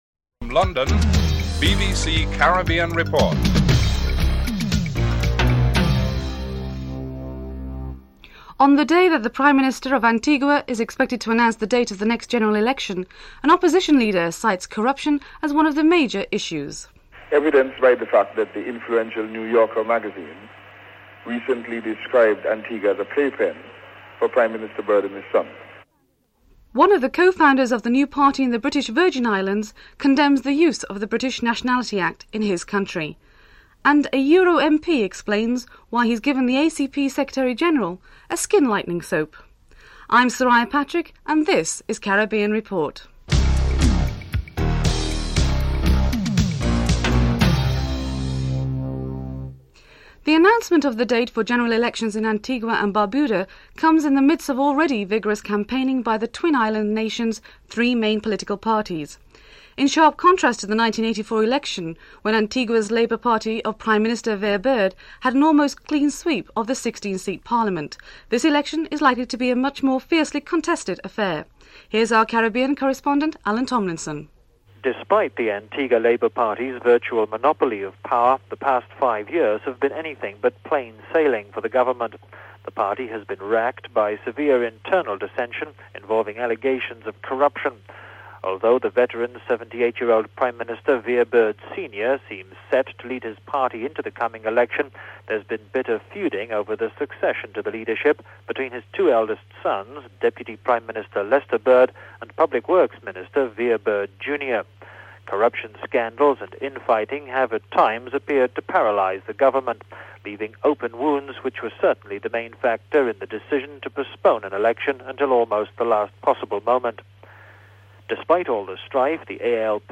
4. Financial News (06:43-08:20)
7. Sporting segment covers the second one-day international cricket match between West Indies and England. Christopher Martin Jenkins reports (13:08-14:47)